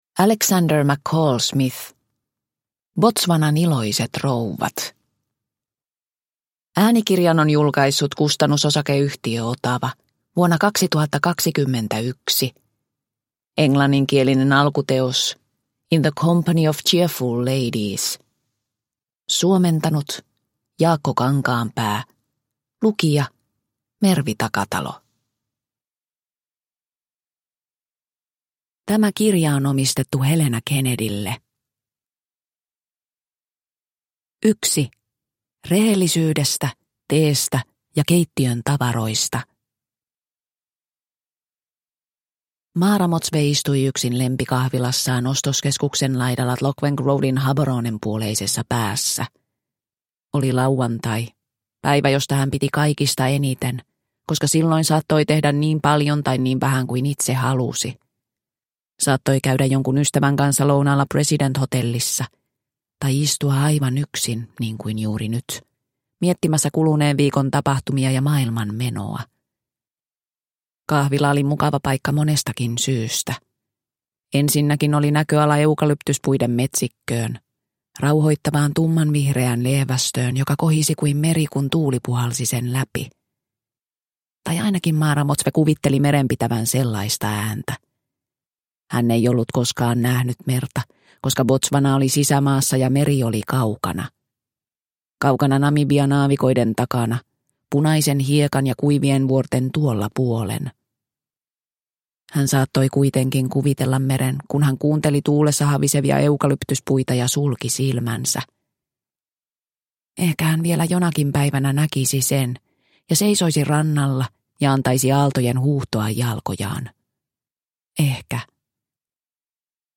Botswanan iloiset rouvat – Ljudbok – Laddas ner